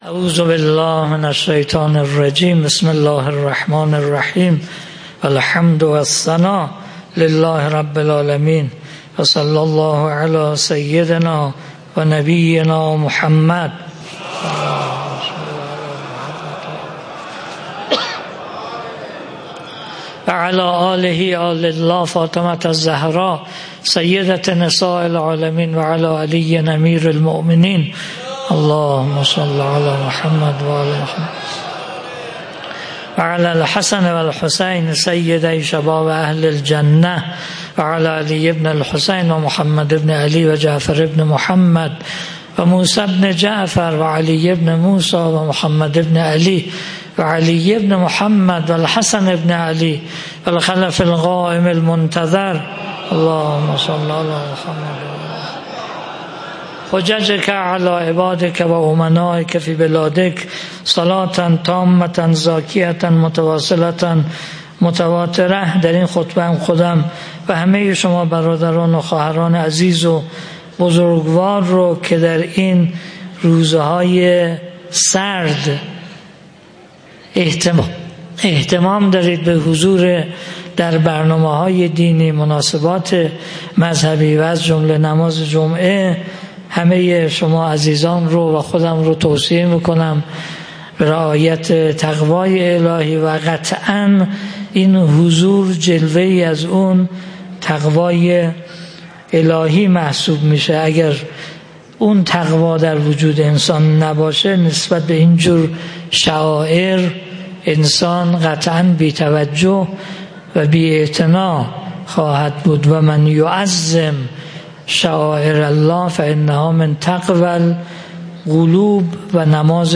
خطبه-دوم-5.mp3